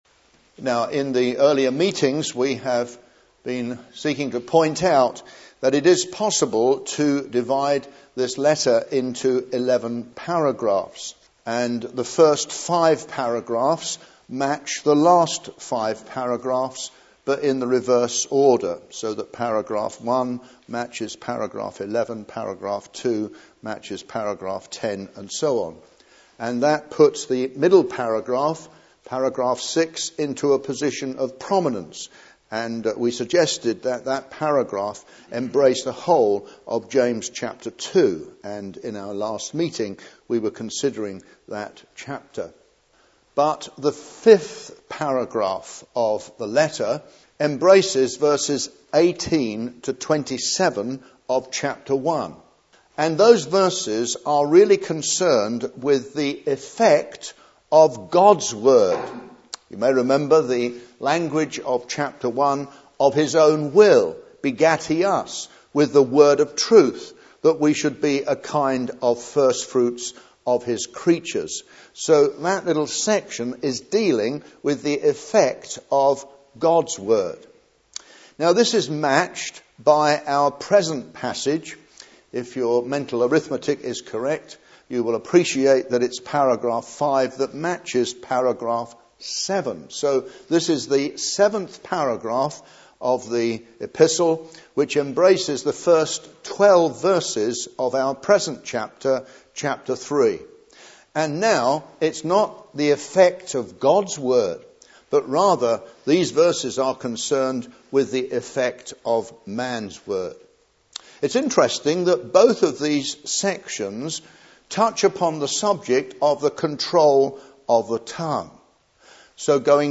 He gives very practical exhortations concerning gossip and the evil of sowing discord among brethren (Message preached 28th May 2009)